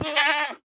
sounds / mob / goat / hurt2.ogg
hurt2.ogg